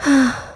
Ripine-Vox-Deny_kr.wav